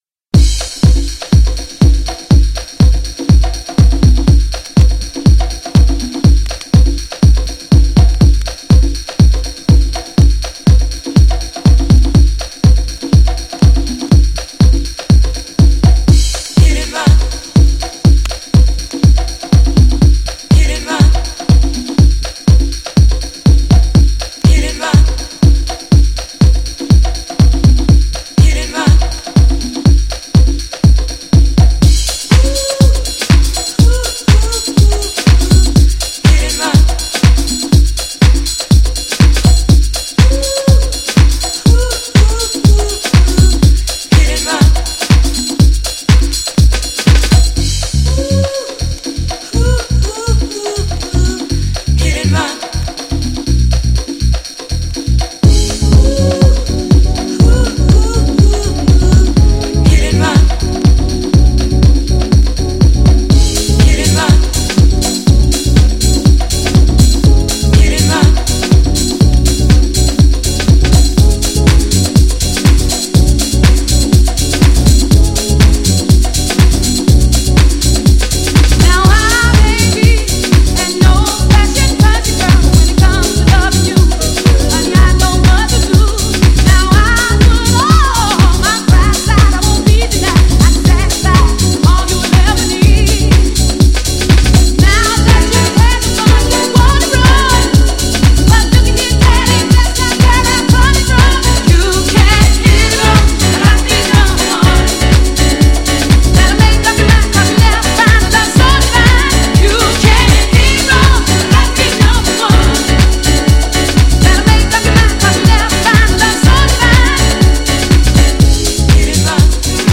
GENRE House
BPM 116〜120BPM